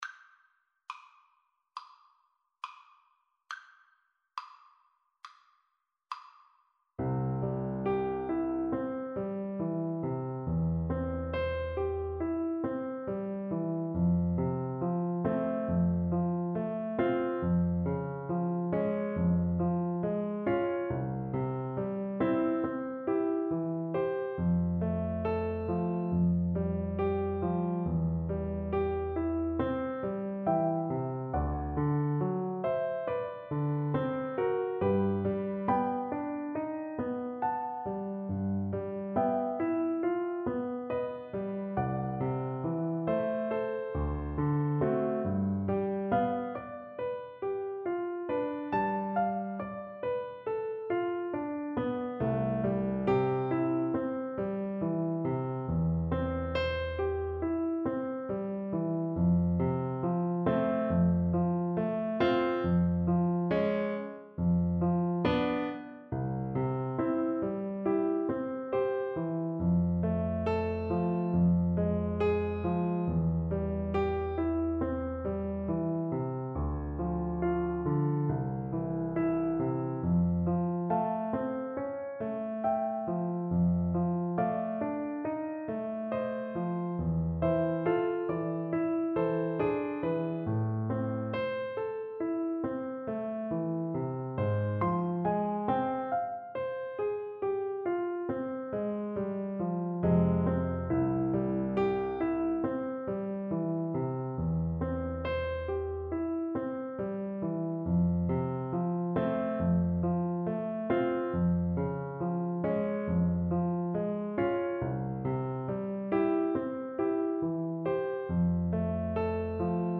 Play (or use space bar on your keyboard) Pause Music Playalong - Piano Accompaniment Playalong Band Accompaniment not yet available transpose reset tempo print settings full screen
French Horn
C major (Sounding Pitch) G major (French Horn in F) (View more C major Music for French Horn )
Zart.
2/2 (View more 2/2 Music)
Classical (View more Classical French Horn Music)